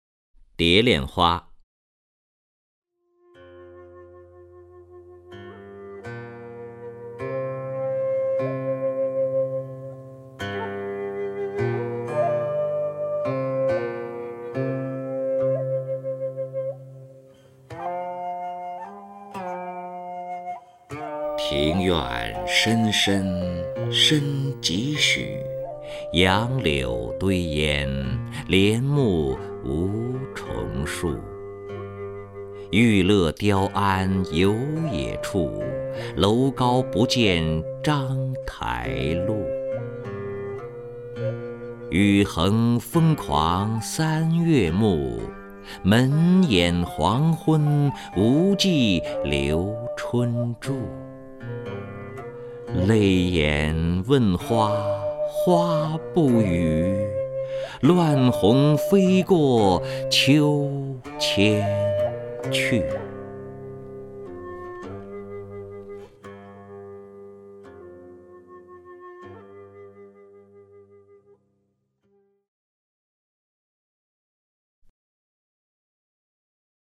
首页 视听 名家朗诵欣赏 任志宏
任志宏朗诵：《蝶恋花·庭院深深深几许》(（北宋）欧阳修)　/ （北宋）欧阳修